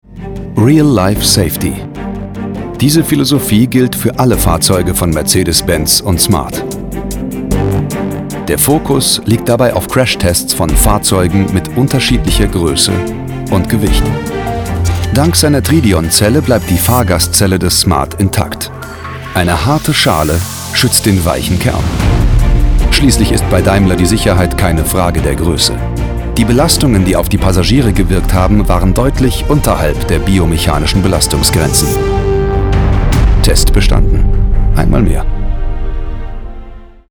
deutscher Profi - Sprecher.
Sprechprobe: Industrie (Muttersprache):
In one line I can describe my voice as: male, sympathetic and very flexible.